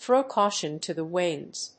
アクセントthrów [flíng] cáution to the wínds